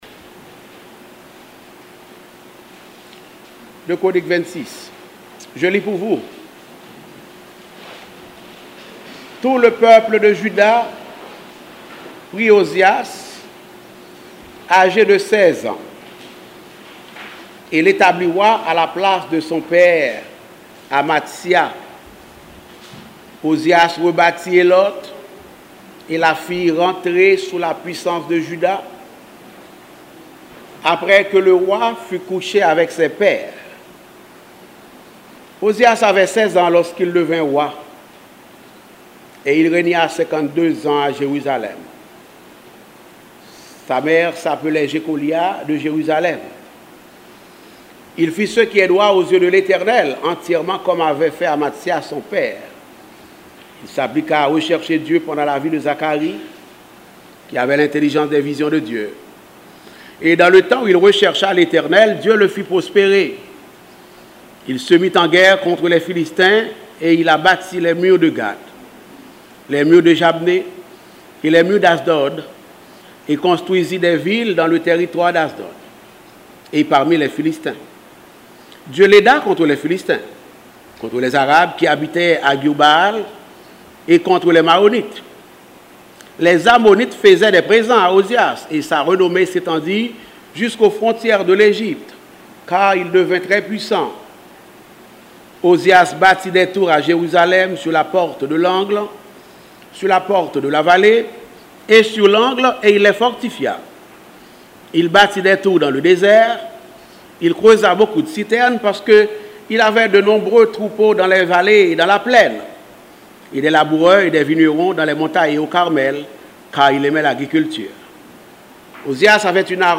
Passage: 2 Chroniques 26 Type De Service: Dimanche matin